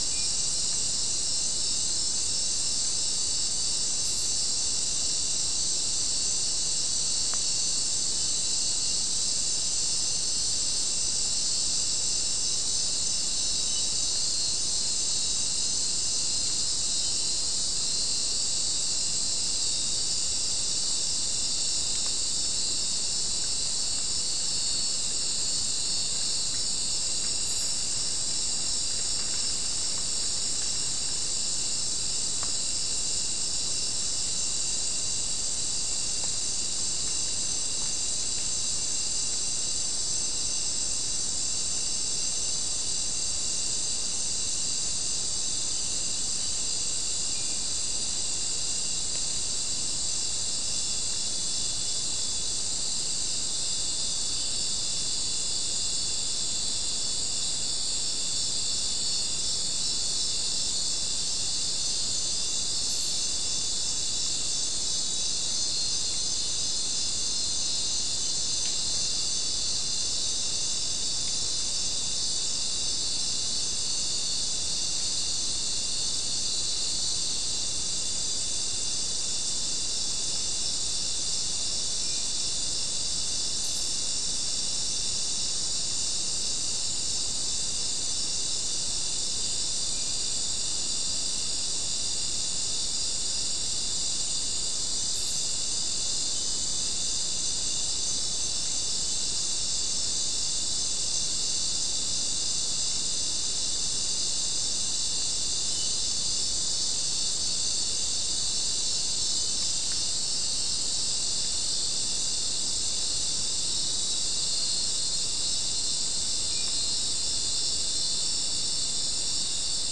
Soundscape Recording Location: South America: Guyana: Sandstone: 2
Recorder: SM3